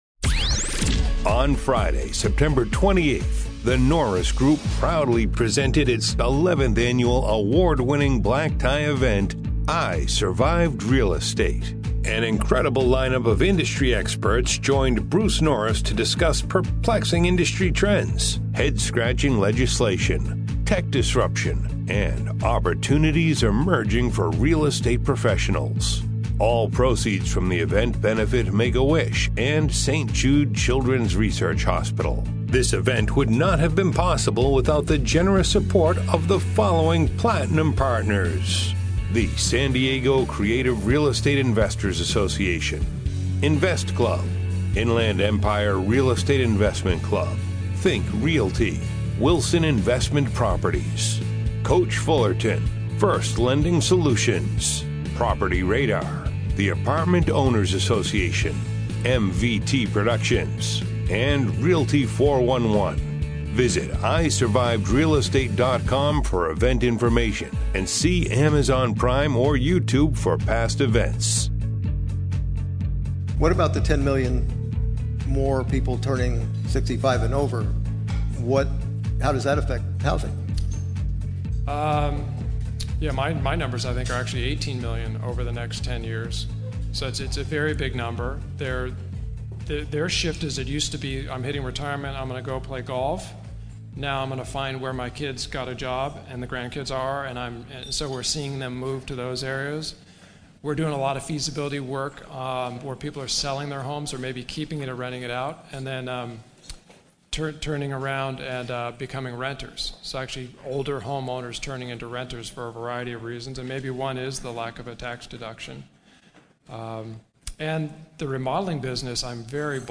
This week is Part 4 of the radio show covering I Survived Real Estate 2018. Covered in this segment is Artificial Intelligence, Zillow and why they decided to jump in to the iBuyer space, potential competition between iBuyers, realtors, and investors, whether this iBuyer model worked form 2008-2010, the amount of Wall Street Companies that would still be left in the private money space following a recession, 3D printing and the modular space, the technology they are most excited for next year, and much more.